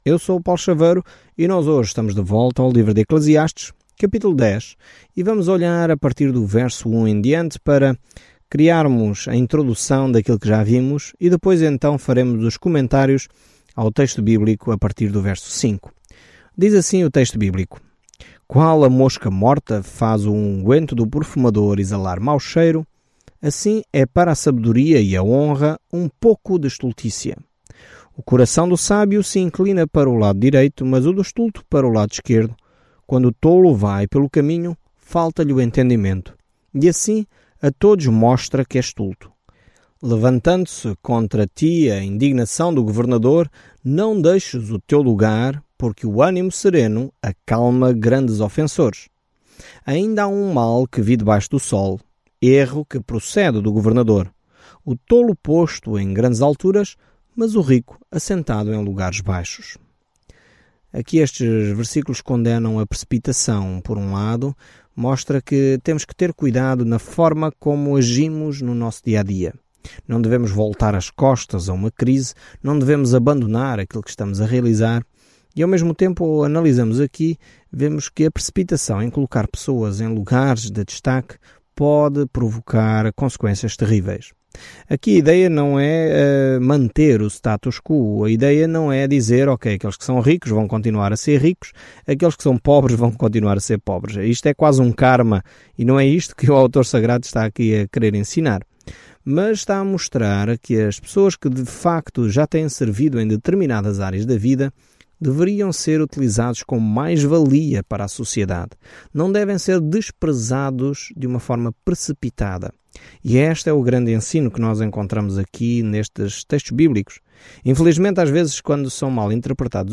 Escritura ECLESIASTES 10:5-20 ECLESIASTES 11:1-8 Dia 10 Iniciar este Plano Dia 12 Sobre este plano Eclesiastes é uma autobiografia dramática da vida de Salomão quando ele tentava ser feliz sem Deus. Viajando diariamente por Eclesiastes, você ouve o estudo em áudio e lê versículos selecionados da palavra de Deus.